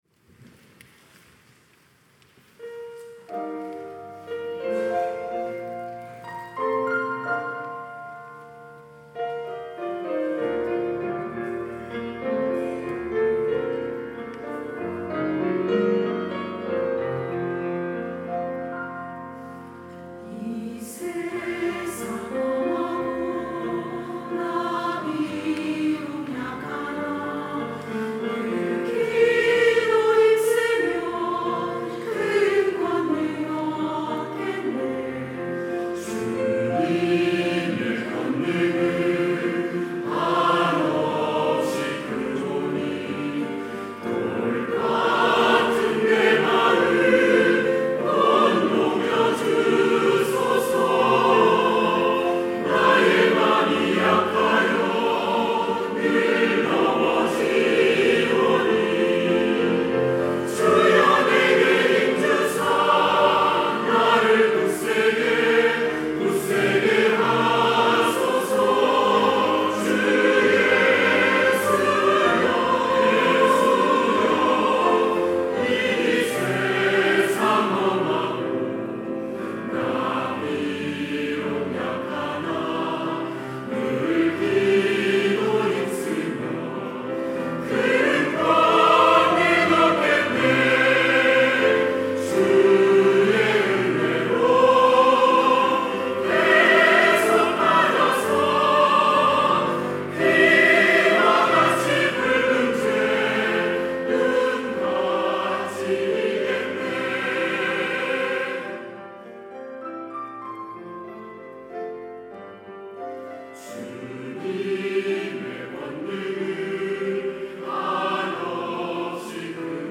할렐루야(주일2부) - 이 세상 험하고
찬양대